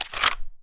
gun_shotgun_pickup_01.wav